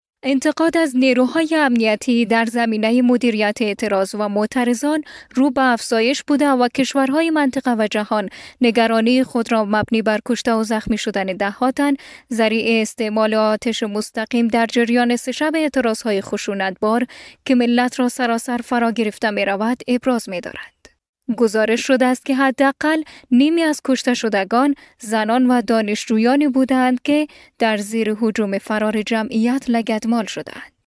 Female
News